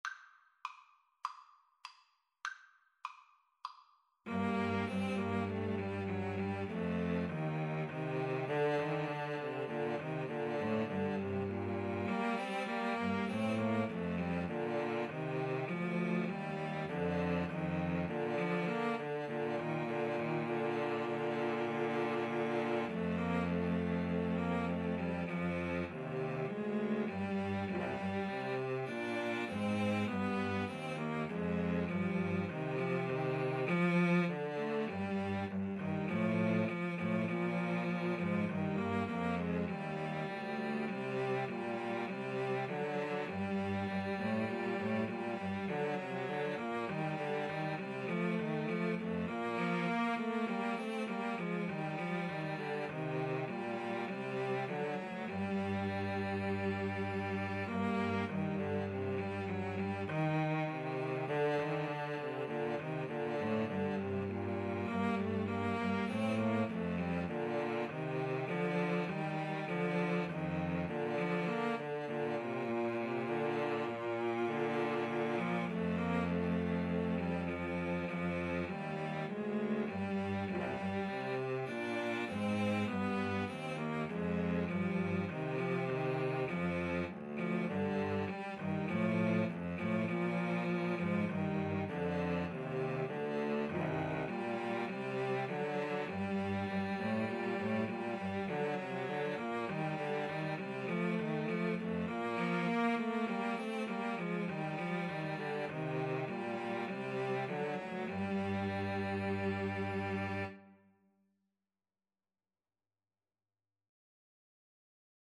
Classical (View more Classical Cello Trio Music)